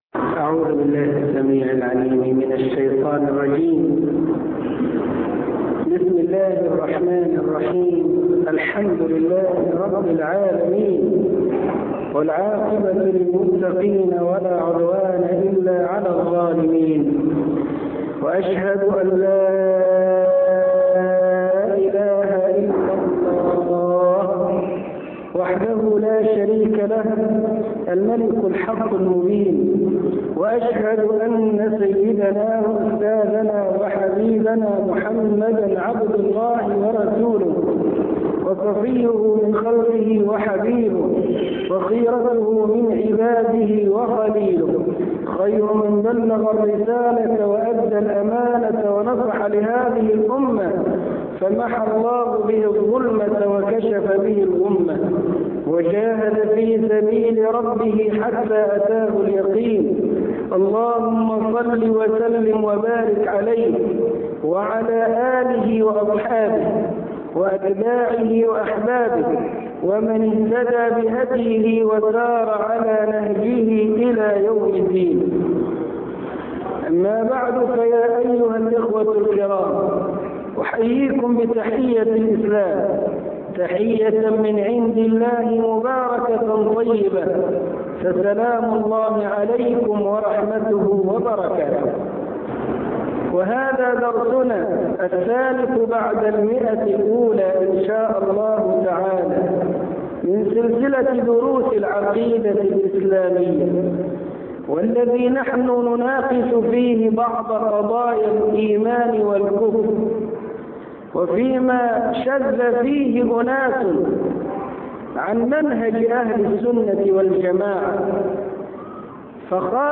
عنوان المادة الدرس(103) الرد على شبهات التكفير -1- شرح العقيده الإسلاميه تاريخ التحميل الأربعاء 24 فبراير 2021 مـ حجم المادة 32.13 ميجا بايت عدد الزيارات 198 زيارة عدد مرات الحفظ 85 مرة إستماع المادة حفظ المادة اضف تعليقك أرسل لصديق